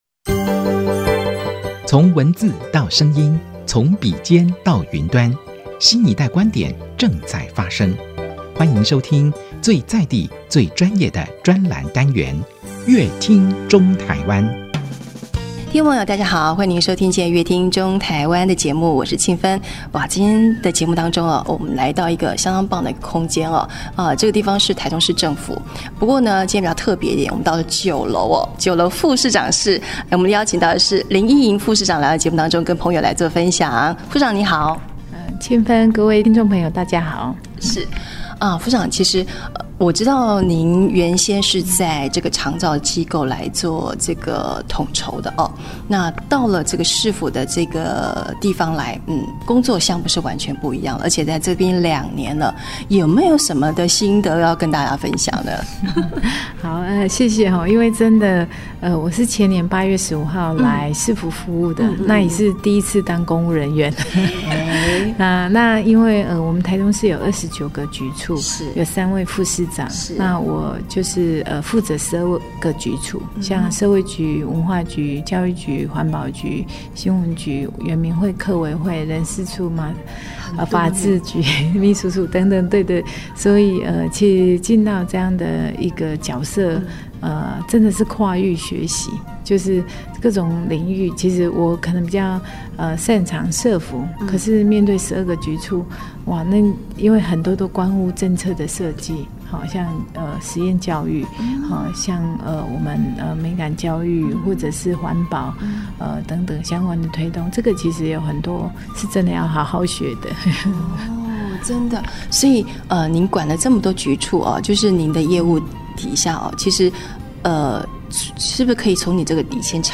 本集來賓：臺中市政府林依瑩副市長 本集主題：「從社福到公務體系 用《心》翻轉大台中」 本集內容： 談到「不老騎